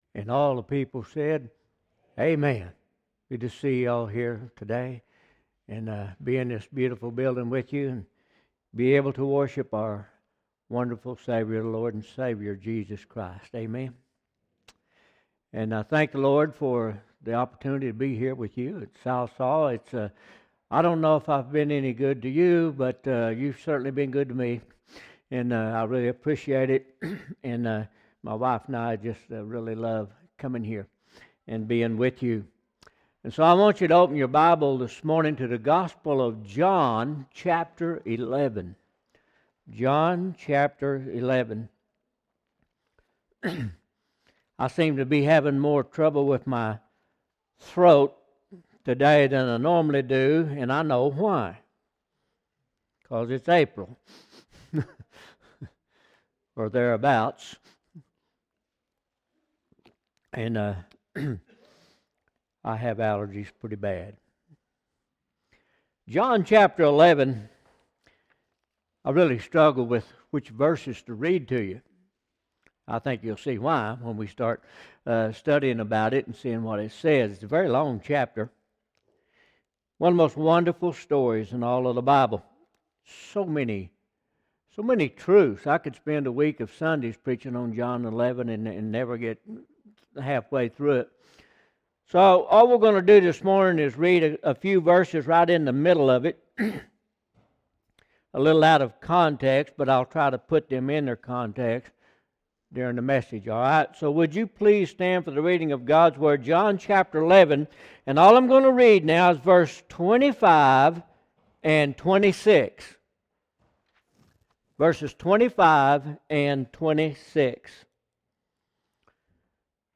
John 11:1-26 Service Type: Morning Service Bible Text